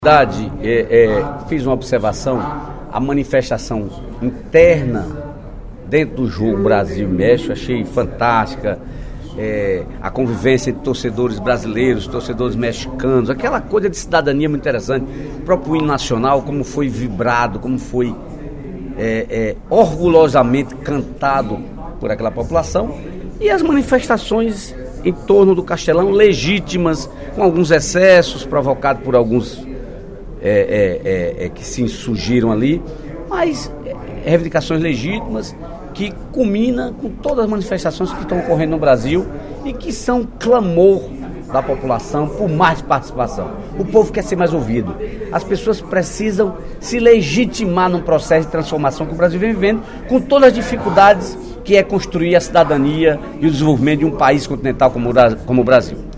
O deputado Dedé Teixeira (PT) destacou, no primeiro expediente da sessão plenária desta quinta-feira (20/06), as manifestações de ontem nas proximidades da Arena Castelão, durante a partida Brasil x México da Copa das Confederações.